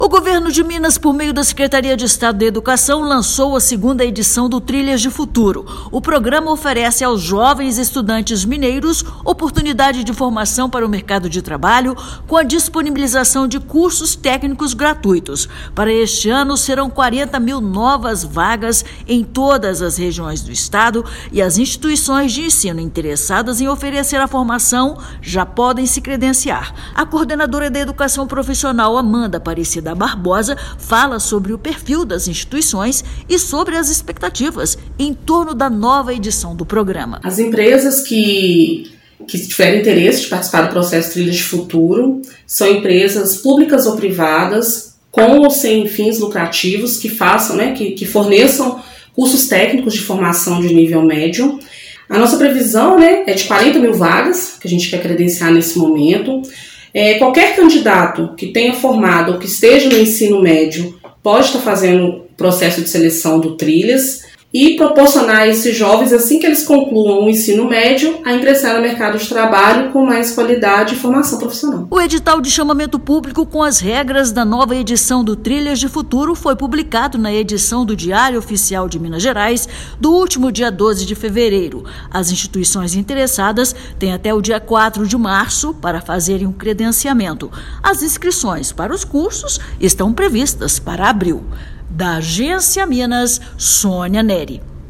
Instituições interessadas em disponibilizar as formações já podem se credenciar. Ouça a matéria de rádio.